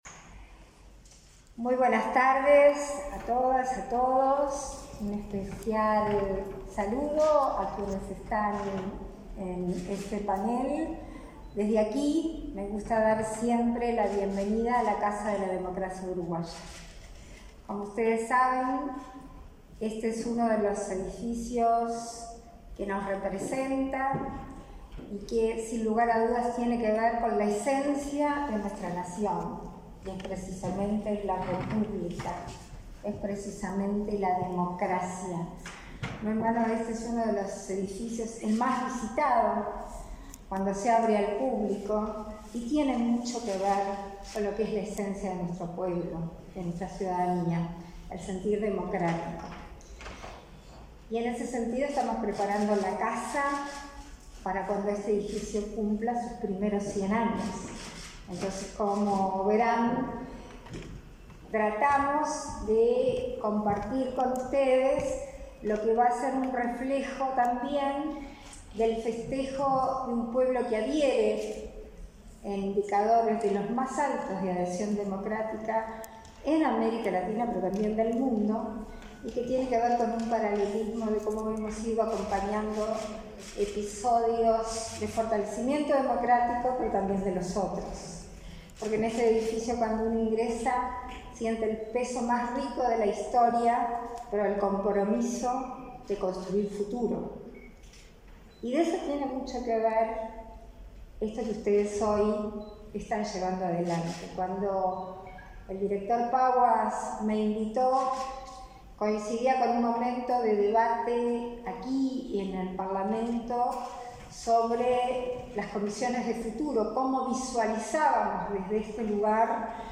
Palabras de autoridades en el Palacio Legislativo
Palabras de autoridades en el Palacio Legislativo 17/10/2023 Compartir Facebook X Copiar enlace WhatsApp LinkedIn La vicepresidenta de la República, Beatriz Argimón, y el director ejecutivo de la Agesic, Hebert Paguas, participaron en el 11.° Seminario de Acceso a la Información Pública, realizado este martes 17 en el Palacio Legislativo.